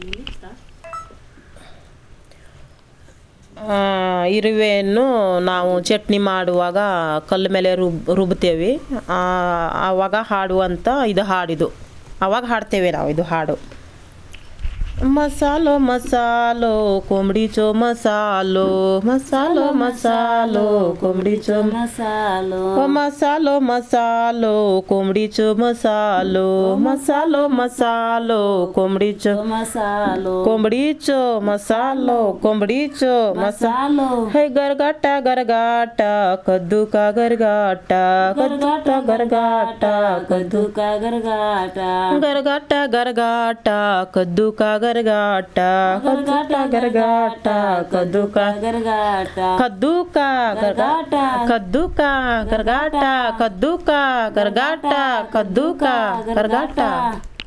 Performance of traditional folk song 'Masalo